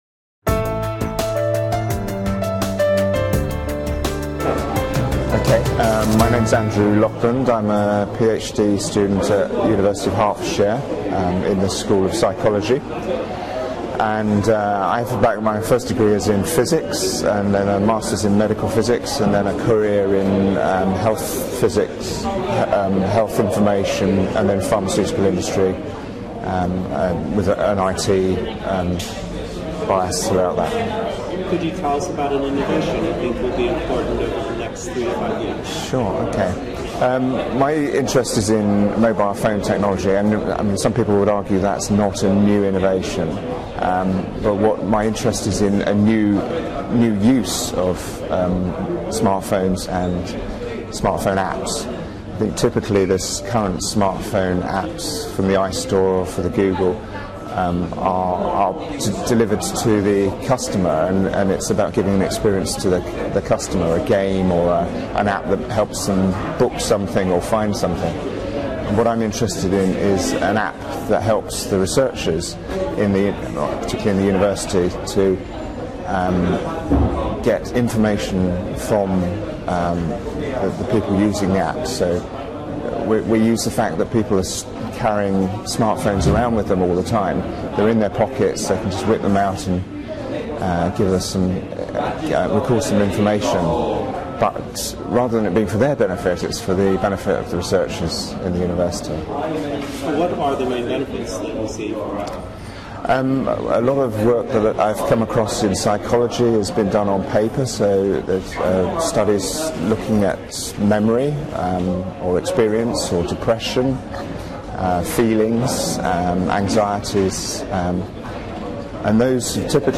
Recorded at Dev8D 2012, this interview explains how the rapidly increasing availability of smart phones presents researchers with opportunities to improve the reliability and scope of research data.
Transcript: Lightning interview